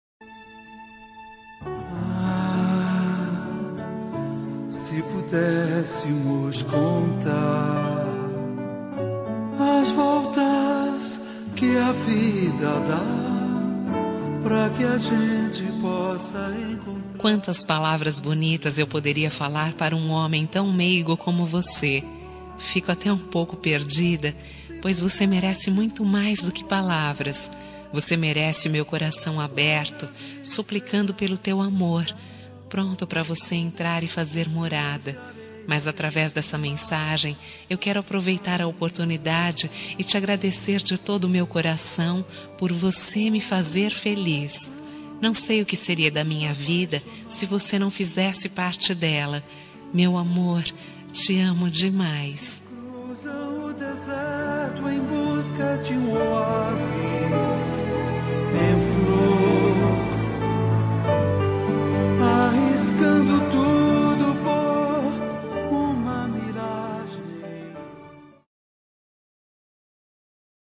Agradecimento – Voz Feminina – Cód: 09813